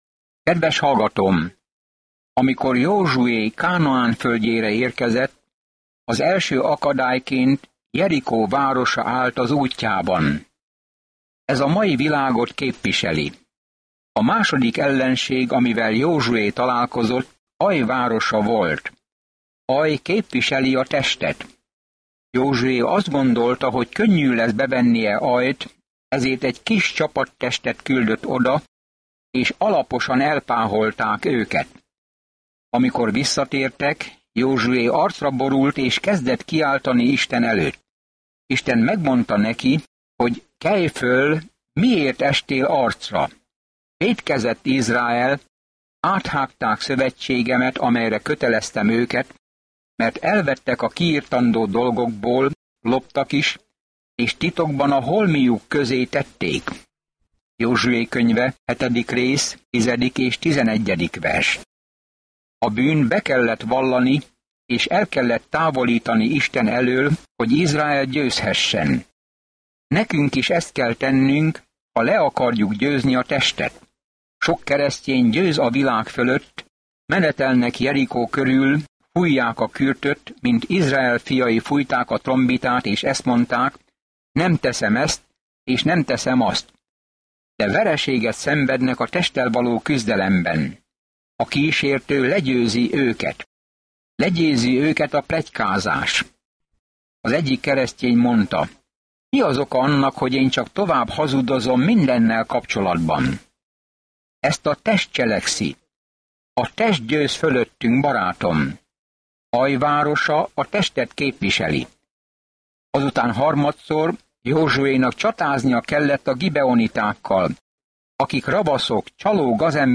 Szentírás Efezus 6:10-15 Nap 24 Olvasóterv elkezdése Nap 26 A tervről Az efézusiakhoz írt levél elmagyarázza, hogyan kell Isten kegyelmében, békéjében és szeretetében járni, a csodálatos magasságokból, hogy mit akar Isten gyermekei számára. Napi utazás az efézusi levélben, miközben hallgatod a hangos tanulmányt, és olvasol válogatott verseket Isten szavából.